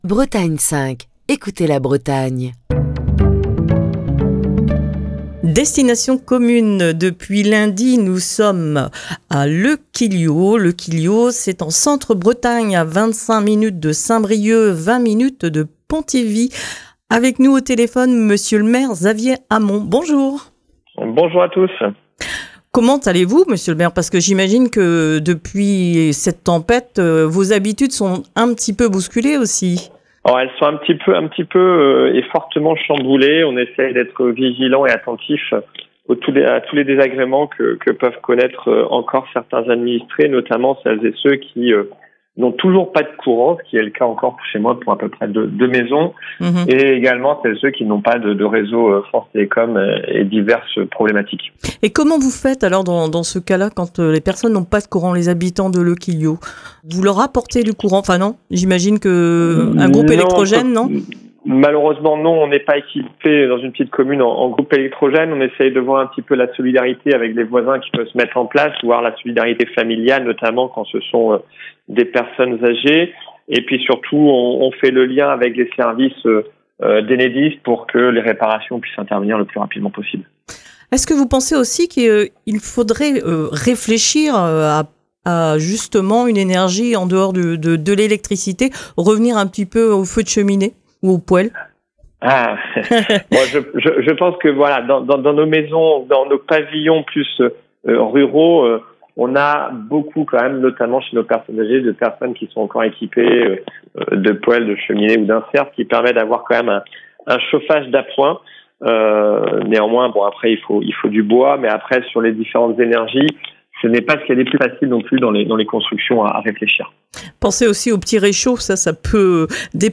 Xavier Hamon, le maire de Le Quillio est au micro de Destination commune.